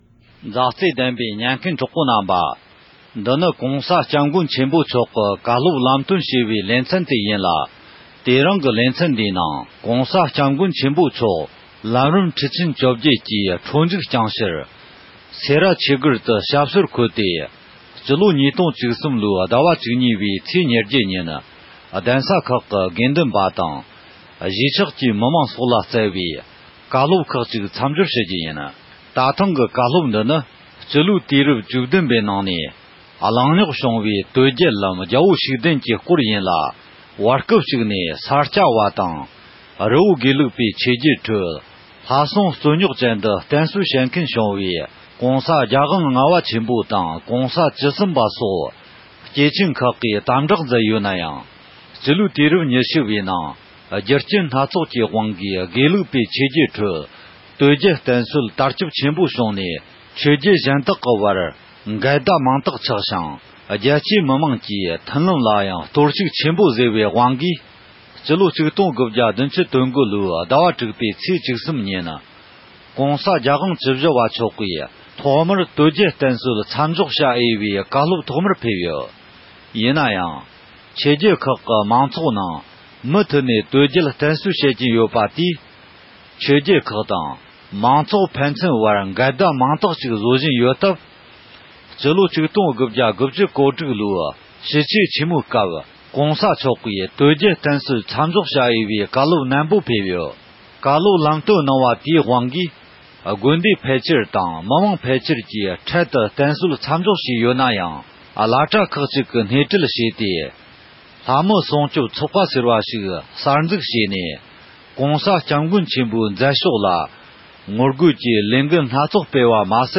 ༸གོང་ས་མཆོག་ནས་རྒྱ་གར་ལྷོ་ཕྱོགས་སེ་ར་ཐེག་ཆེན་གླིང་དུ་ཆོས་འབྲེལ་གནང་སྐབས། དོལ་རྒྱལ་སྐོར་གལ་ཆའི་བཀའ་སློབ་ཁག་ཅིག་གནང་ཡོད་པ་རེད།